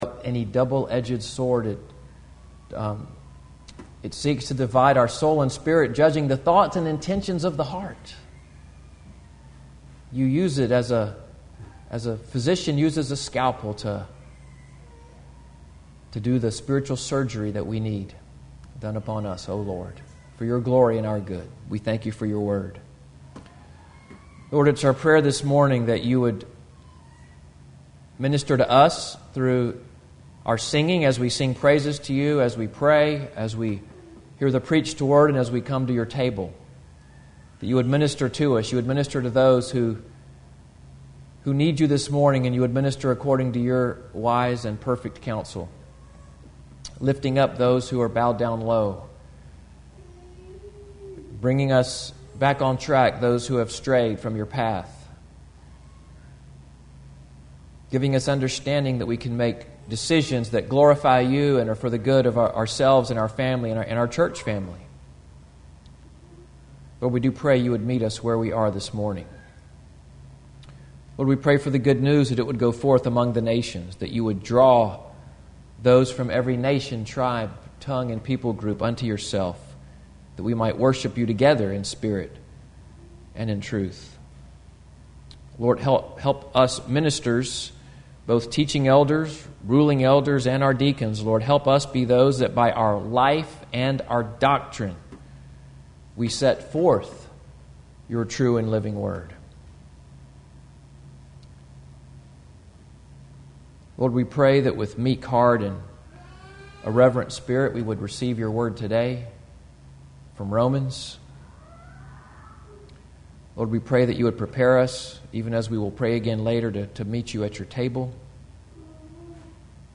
8/11/2024 Pastorial Prayer